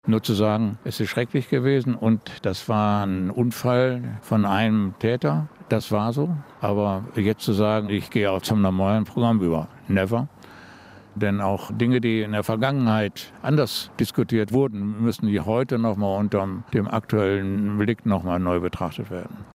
Die Verantwortung für den Unfall vorige Woche liege allein beim Fahrer, aber man könne jetzt nicht einfach zur Tagesordnung übergehen, so Oberbürgermeister Mast-Weisz am Abend in der Bezirksvertretung Lennep. Stadt und Polizei sollen künftig noch enger zusammenarbeiten, um die Raser- und Autoposer-Szene konsequenter in den Blick zu nehmen.